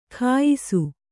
♪ khāyisu